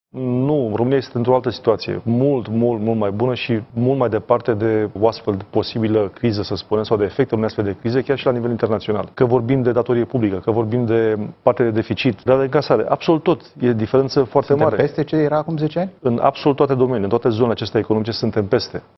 20sept-18-voce-VERIFICAT-Teodorovici-nu-va-fi-criza.mp3